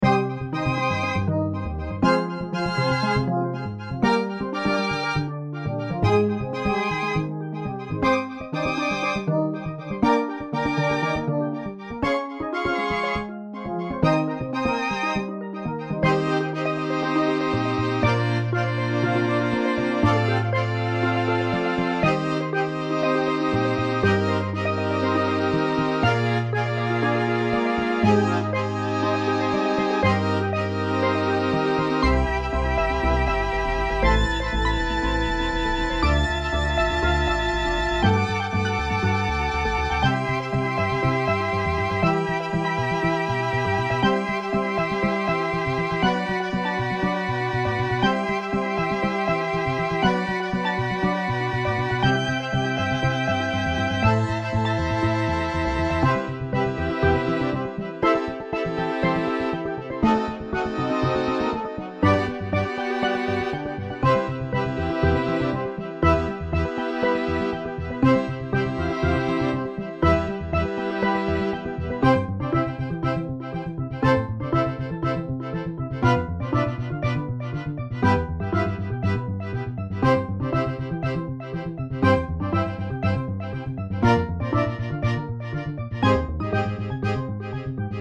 スティールドラム、アコースティックベース、チェロ
BGM